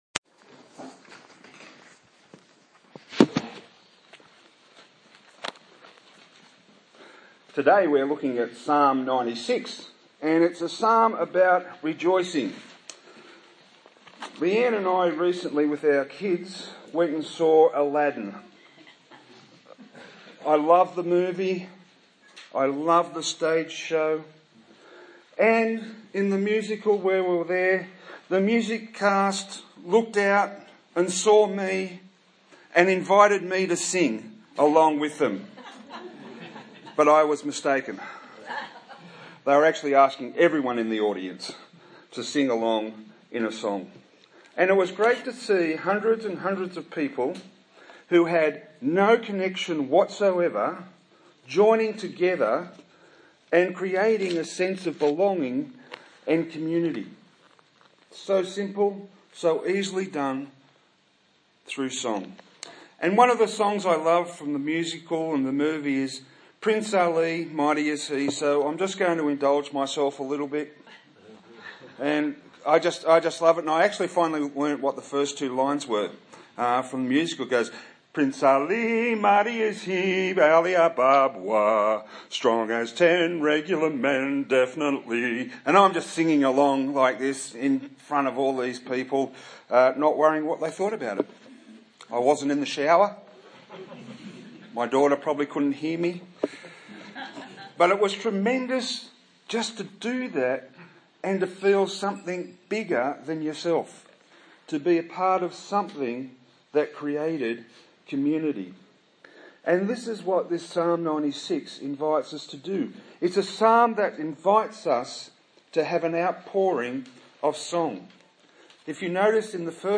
A sermon on Psalm 96
Service Type: Sunday Morning